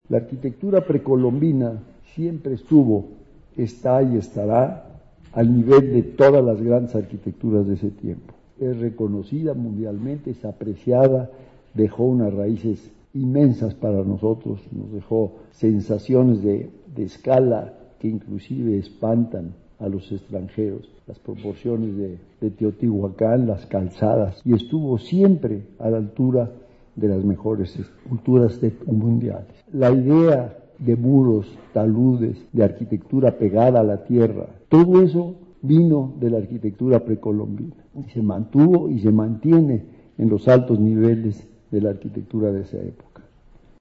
• En la víspera de la recepción del doctorado honoris causa por la UNAM, ofreció la conferencia magistral denominada Raíces, en la que dijo que el mundo está convulsionado por la pérdida de valores fundamentales
Esta situación representa una oportunidad y una responsabilidad para los jóvenes, “están en el momento adecuado, cuentan con todas las ventajas para llevar nuevamente a México a los grandes niveles en este ámbito” señaló ante universitarios reunidos en el auditorio Carlos Lazo, de la FA